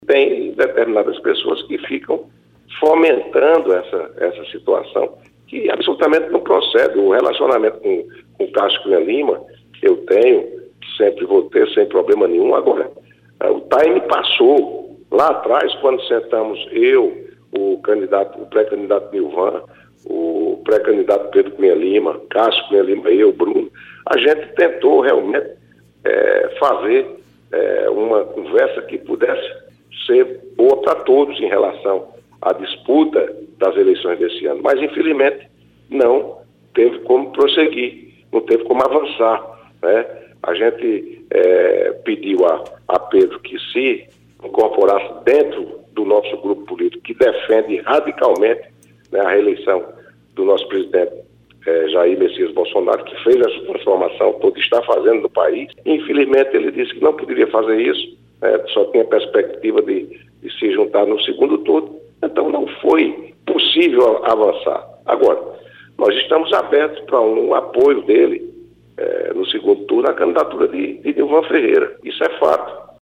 O comentário de Wellington Roberto foi registrado pelo programa Correio Debate, da 98 FM, de João Pessoa, nesta quinta-feira (08/09).